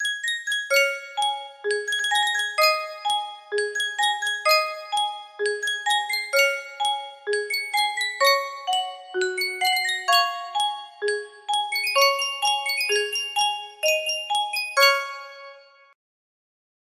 Sankyo Music Box - Down By the Bay XUQ music box melody
Full range 60